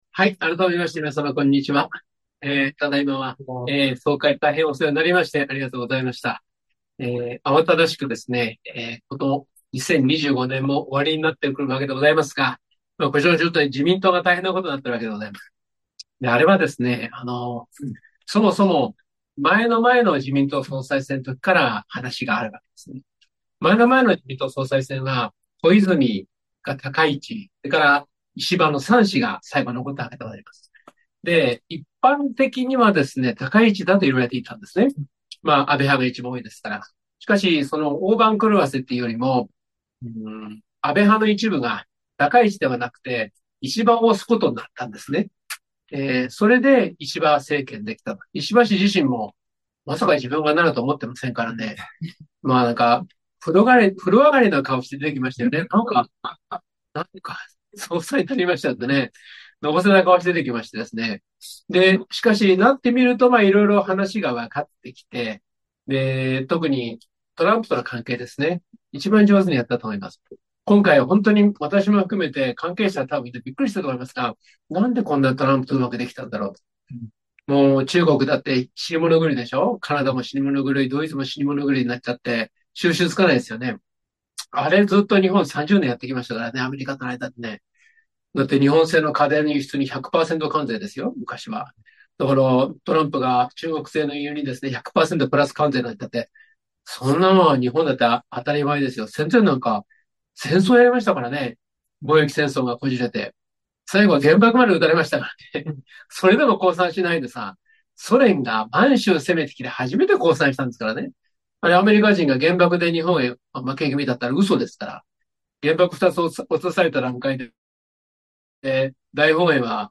総会
おかげさまで、10月13日（祝月・スポーツの日）開催の第23期総会は無事に成立し、すべての議案についてご承認をいただきました。